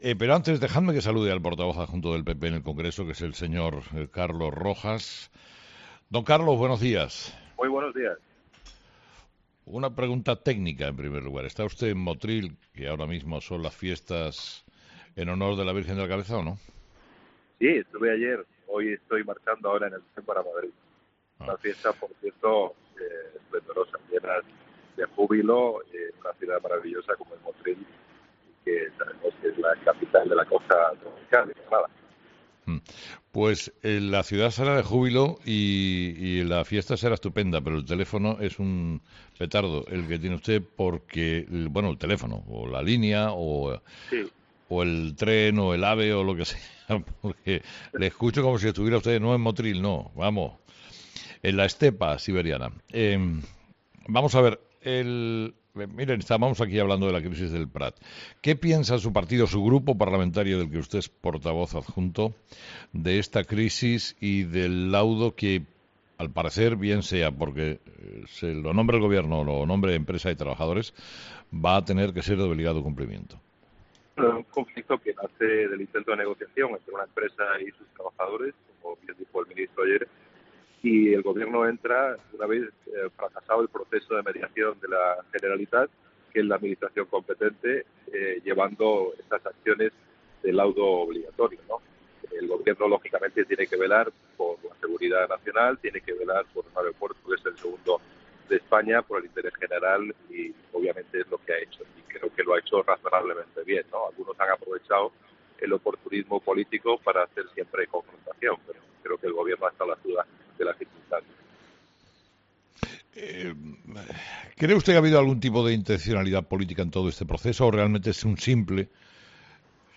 Carlos Rojas, portavoz adjunto del PP en el Congreso, en 'Herrera en COPE'